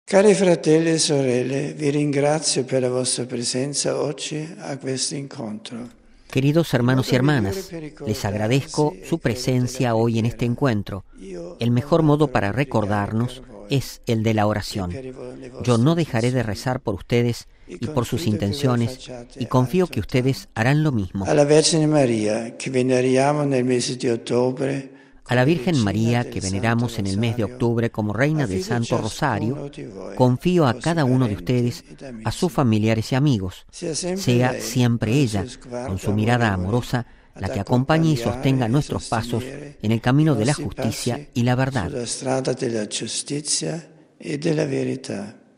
(RV).- Al final de su estancia veraniega, Benedicto XVI saludó esta mañana a la comunidad de Castel Gandolfo, donde el Papa ha transcurrido un periodo de estudio, oración y reposo y donde ha podido apreciar la solicitud y premura de todas las personas que a su alrededor le han brindado asistencia y hospitalidad, tanto a él como a sus colaboradores.